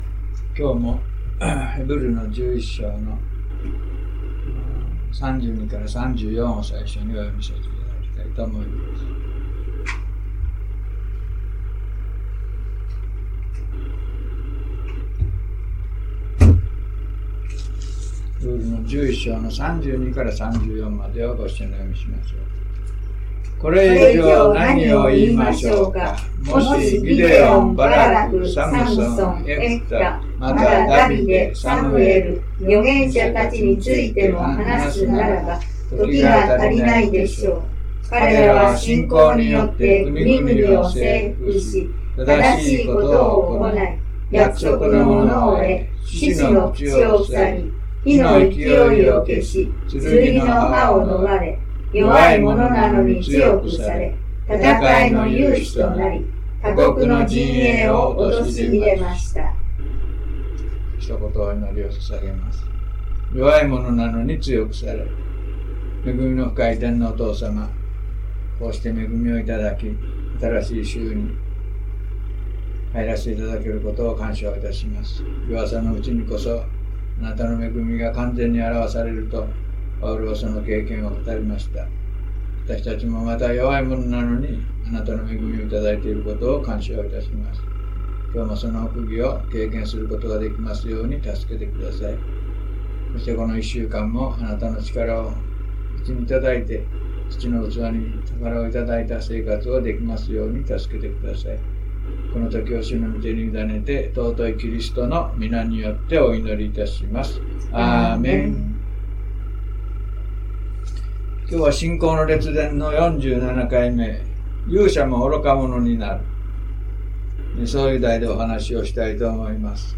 礼拝メッセージ
The_Heroes_of_Faith_47mono.mp3